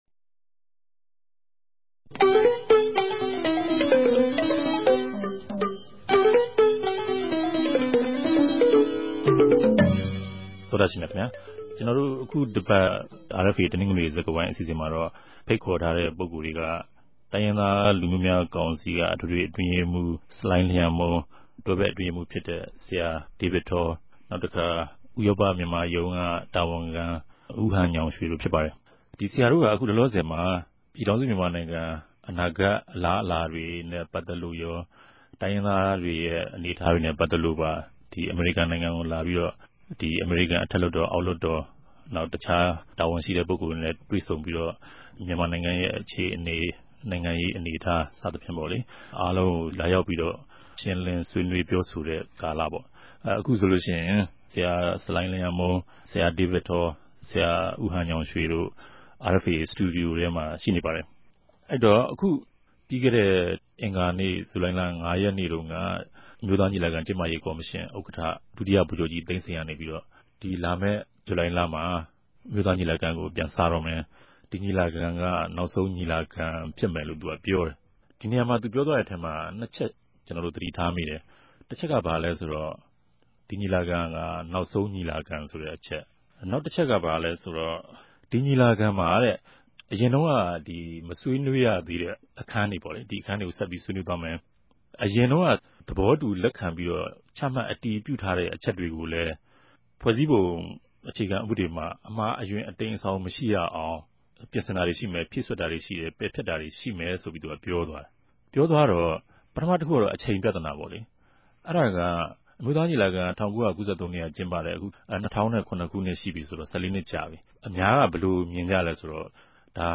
တနဂဿေိံြ ဆြေးေိံြးပြဲစကားဝိုင်း